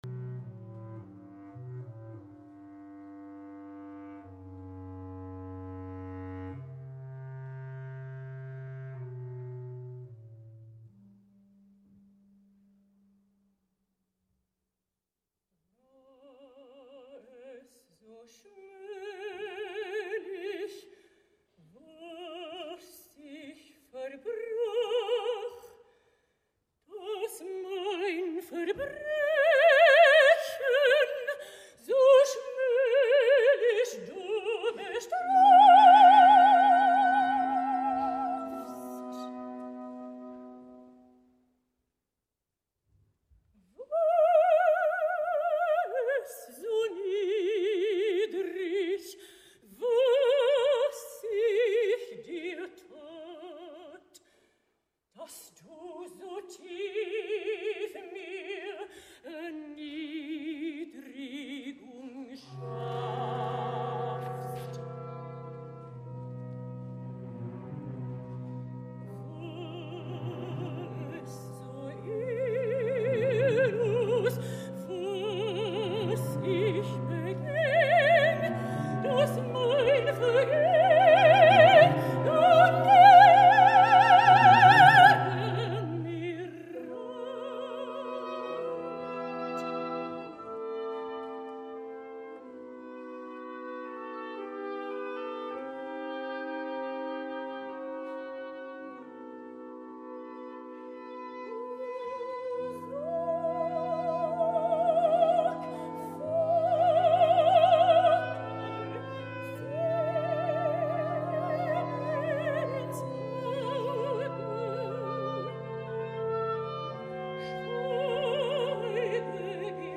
El Festival de Verbier ha celebrat el bicentenari Wagner/Verdi amb un concert operistic dirigint Valeri Gergiev a l’orquestra de Festival, i seguint aquelles pràctiques tant del Metropolitan de Nova York quan es tracta d’homenatjar a algú, han programat dos actes isolats de dues òperes diferents, quelcom que pretén quedar bé amb ambdós compositors alhora que fer algunes combinacions espectaculars de cantants, però que no deixa de ser una espècie de coitus interruptus.